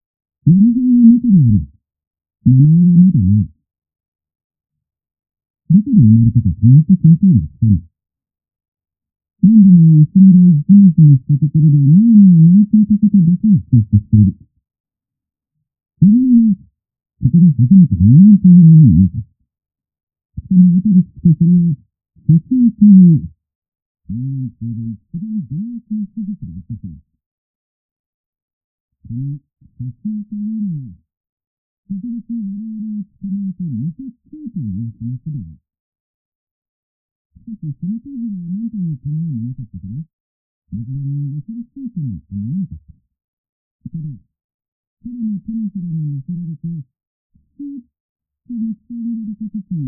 テレビの音には、低音〜高音の音が含まれます。
・テレビの音は、設置前の音 23秒 → 設置後の音 23秒
※壁越しの音声のため、音が曇って歪んでいます。